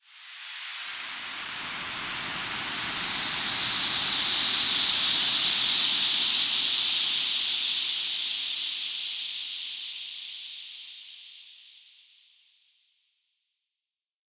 Index of /musicradar/shimmer-and-sparkle-samples/Filtered Noise Hits
SaS_NoiseFilterD-01.wav